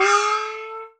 CHINA FX.wav